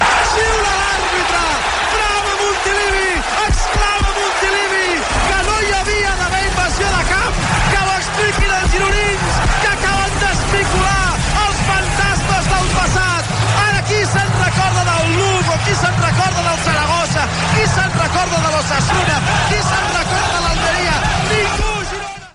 Narració del final del parit Girona Futbol Club Zaragoza, de segona divisió que acaba amb empat. El resultat suposa l'ascens, per primera vegada, del Girona a la primera divisió de la lliga masculina de futbol
Esportiu